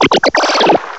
sovereignx/sound/direct_sound_samples/cries/goomy.aif at master